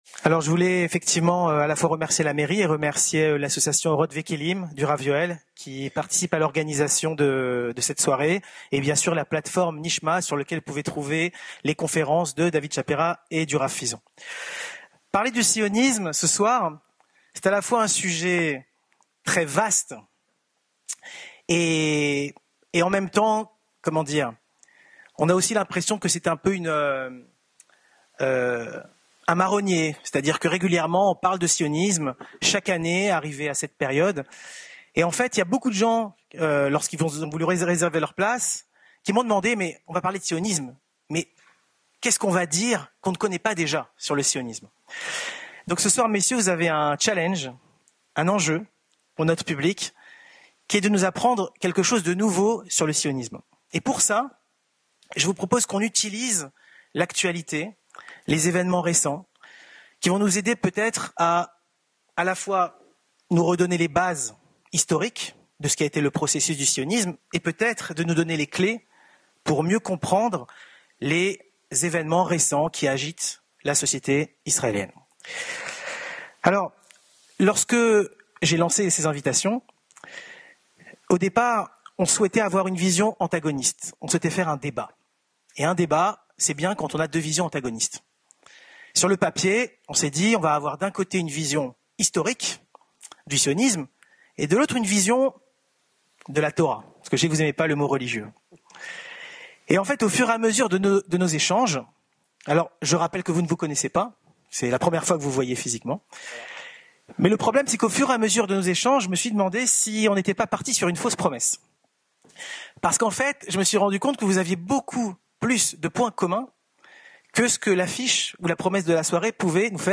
Regards croisés sur le Sionisme : Un Rabbin et un Historien partagent leurs réflexions